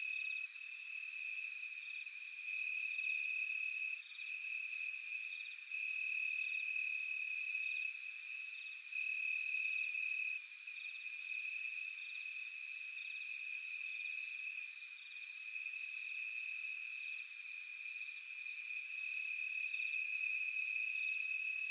insectnight_14.ogg